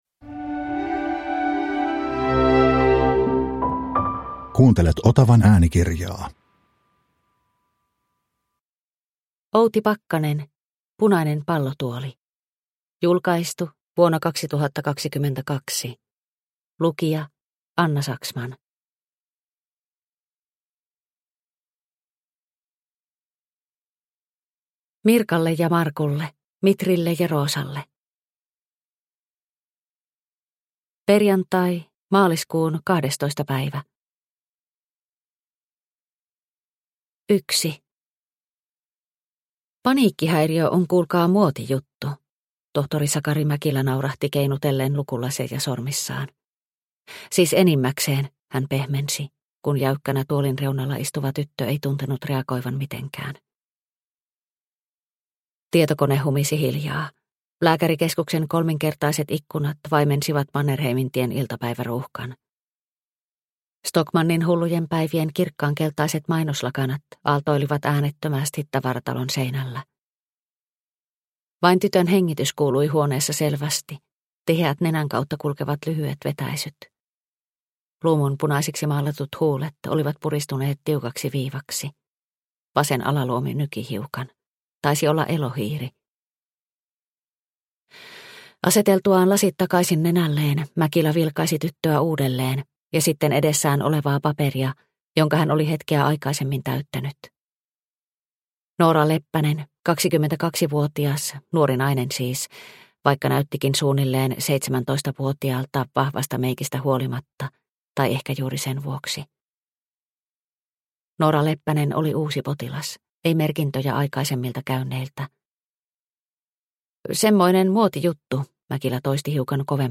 Punainen pallotuoli – Ljudbok – Laddas ner